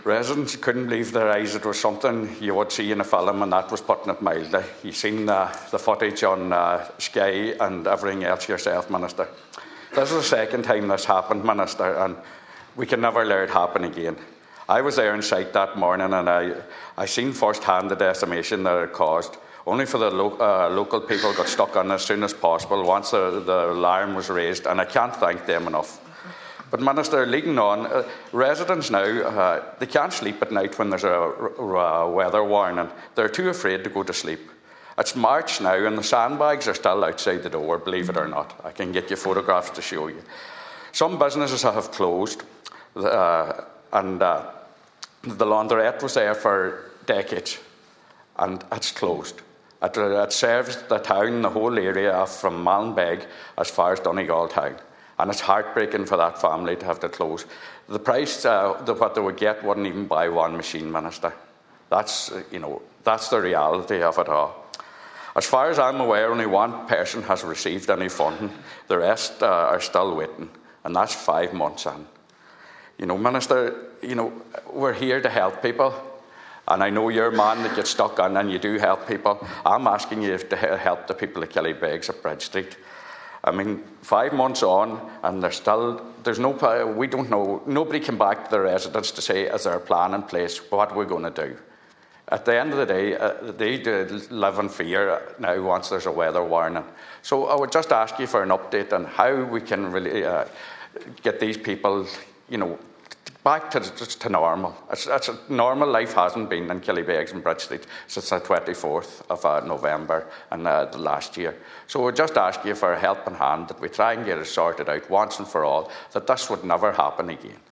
Killybegs flooding raised in Seanad
Donegal Senator Manus Boyle appealed to Minister Kevin Boxer Moran today to progress any plans for emergency flood relief measures for the area.